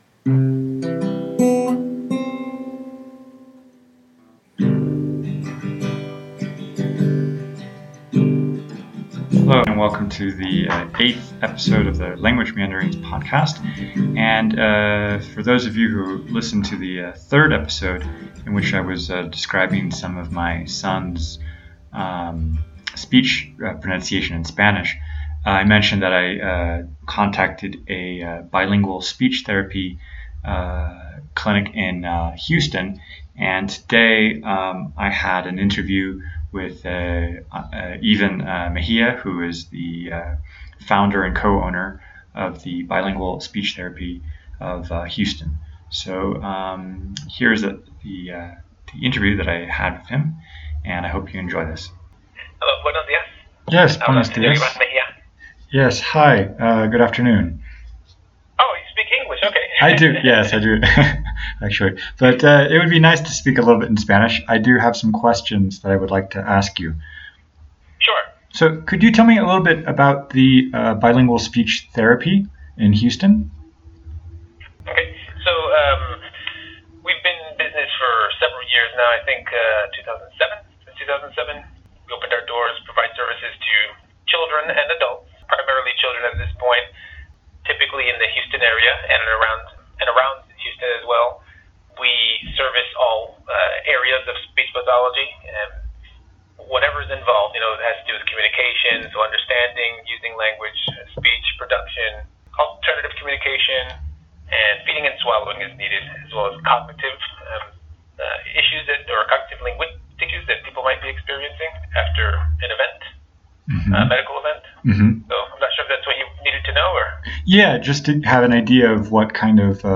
So, here is the interview I had with him and I hope you enjoy this.